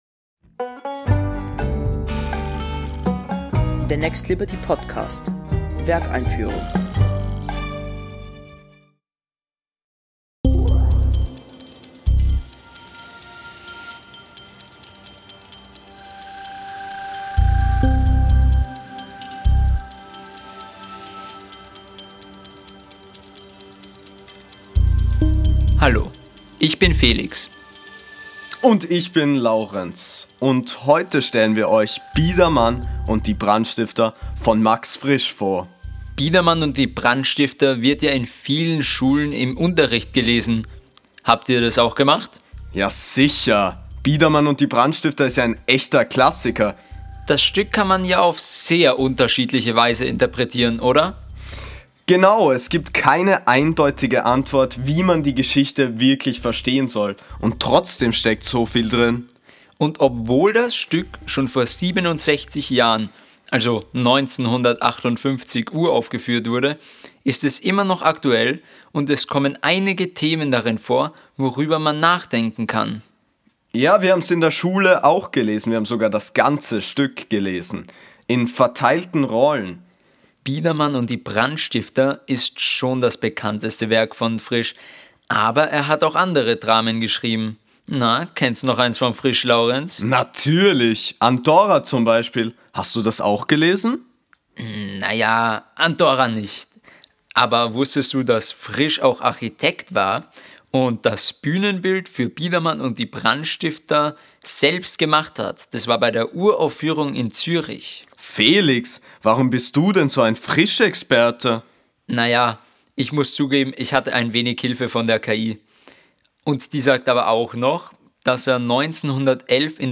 Das und mehr erfahrt ihr in unserer Audio-Einführung, moderiert von zwei Spielclubteilnehmern, mit O-Tönen aus der Inszenierung, Statements des Leading Teams und allen wichtigen Infos zum Stück „to go“.